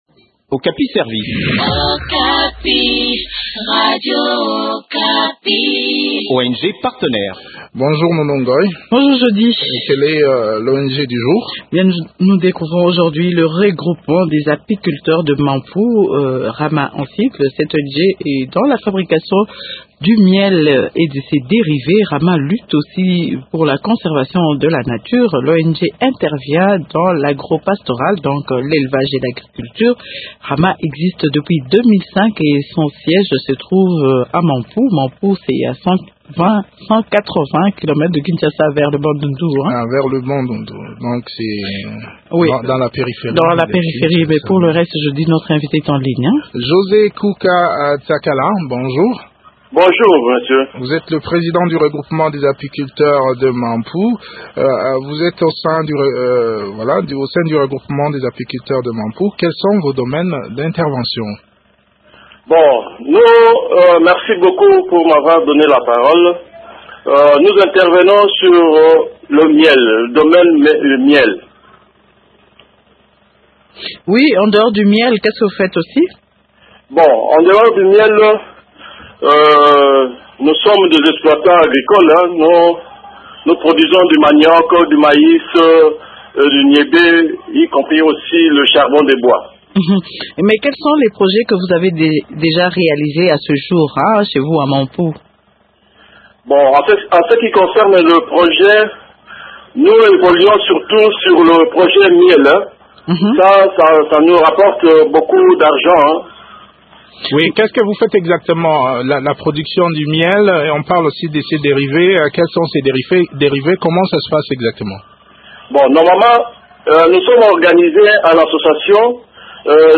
Découvrez les différentes activités du Rama dans cet entretien